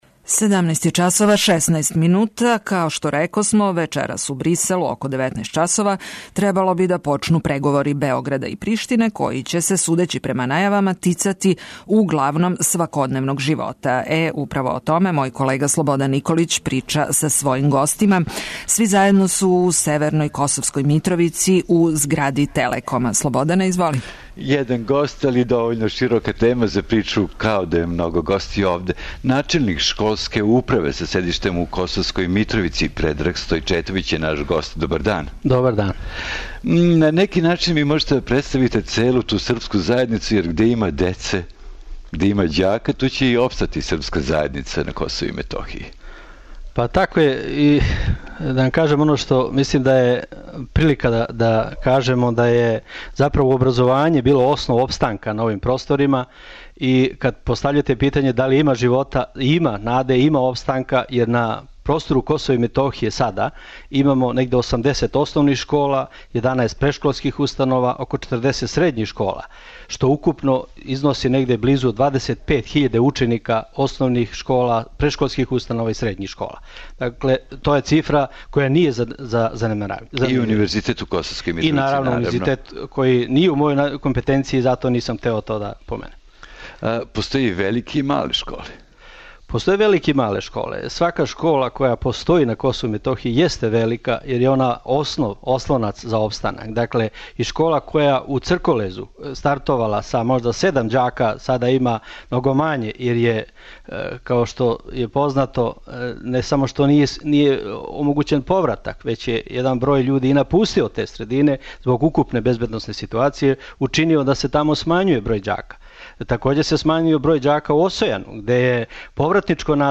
Управо зато, данас разговарамо са Србима који живе на Косову и Метохији и који се непосредно баве телефонима,струјом, таблицама, документима...
Екипа Радио Београда 1 је на лицу места. Програм емитујемо из студија у Косовској Митровици.